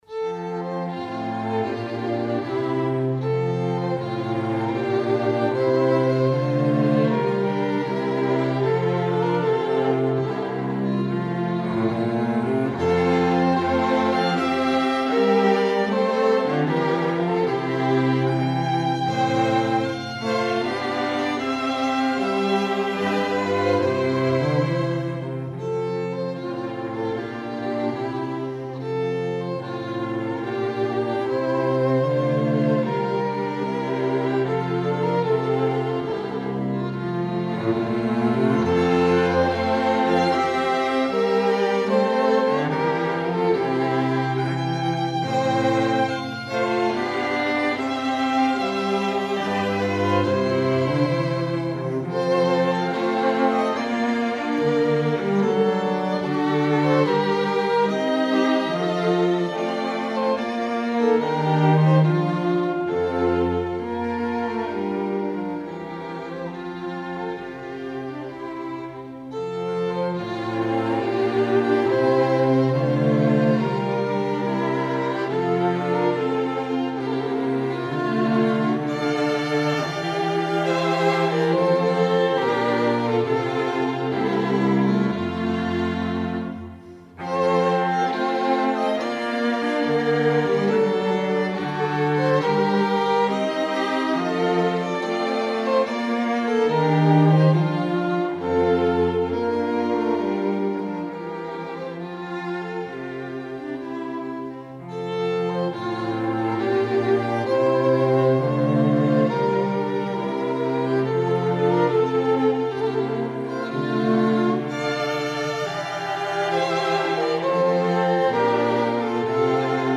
Strings
Each player of this string quartet brings a diversity of experience, from studies with world renown teachers, to performances with great orchestras.